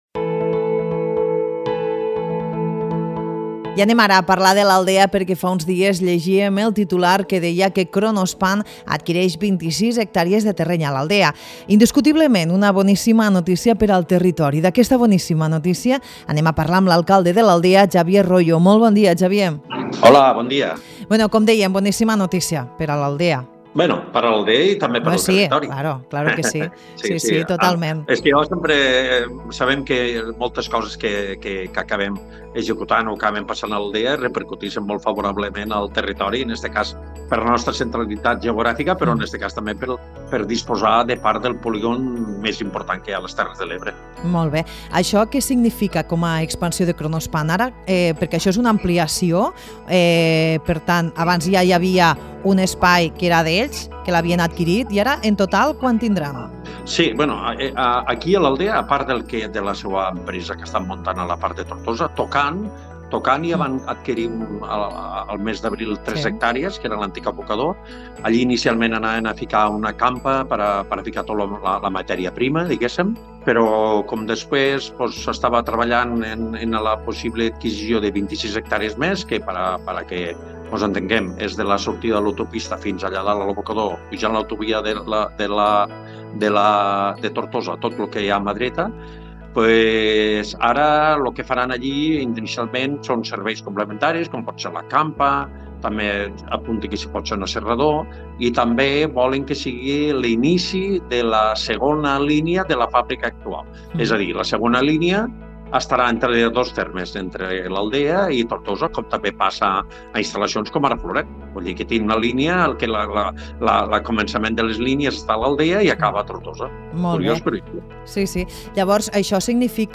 Fa uns dies l’empresa Kronospan va adquirir 26 hectàrees al polígon Catalunya sud, al terme de l’Aldea. L’alcalde del municipi explica que “moltes coses que s’acabi executant a l’Aldea tenen repercussió al territori a causa de la centralitat geogràfica de l’Aldea”. Amb aquesta ampliació, l’empresa alemanya compta amb un total de 29 hectàrees a la localitat.